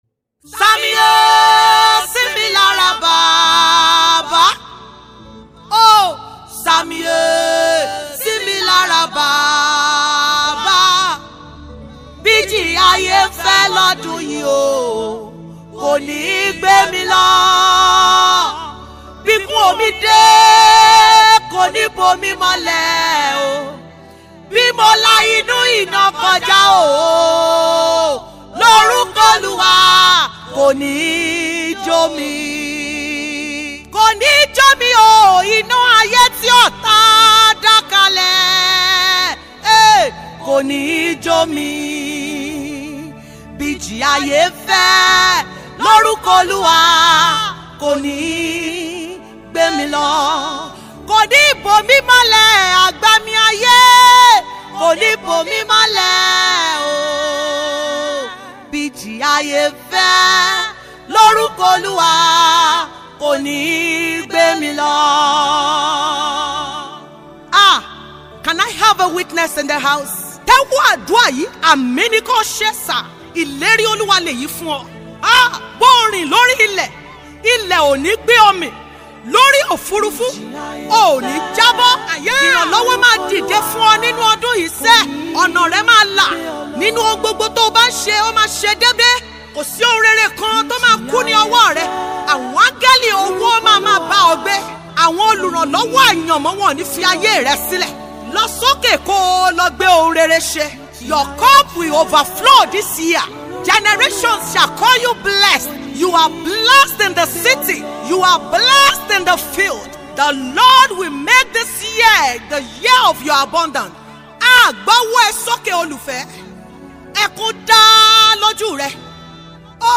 Yoruba Gospel Music
a powerful worship song to uplift and inspire you.